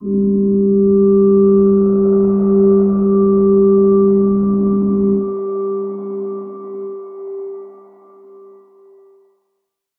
G_Crystal-G4-pp.wav